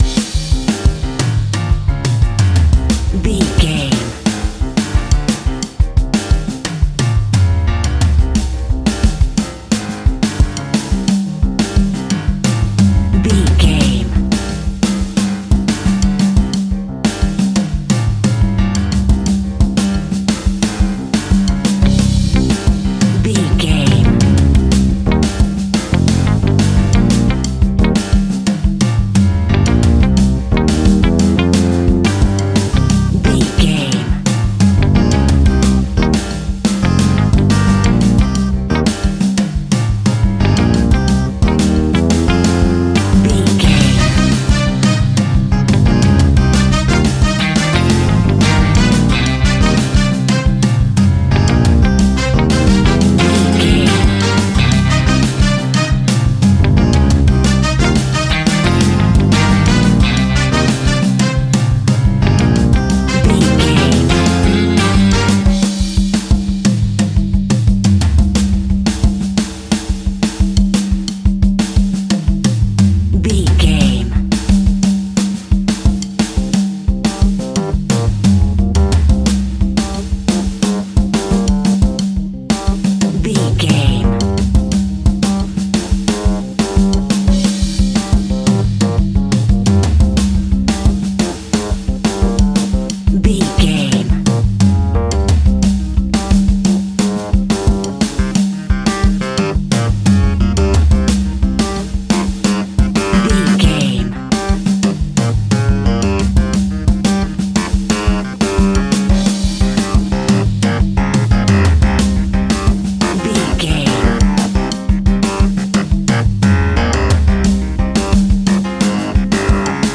Aeolian/Minor
flamenco
latin
drums
bass guitar
percussion
saxophone
trumpet